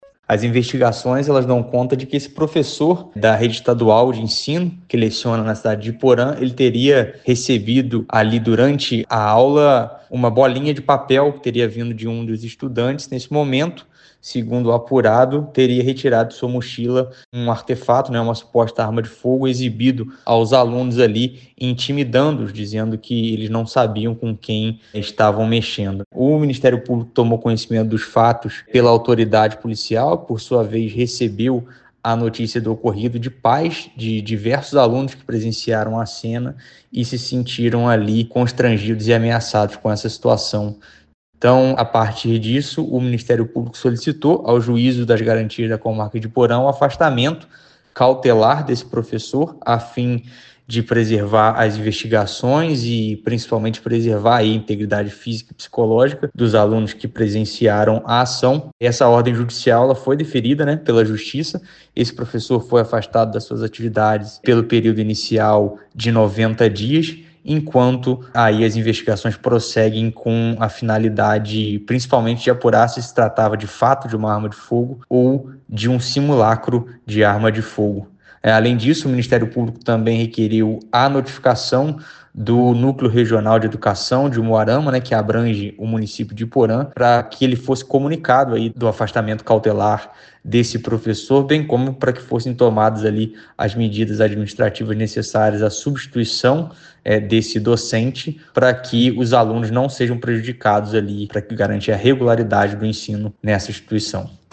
Ouça o que diz o promotor de Justiça Filipe Rocha e Silva.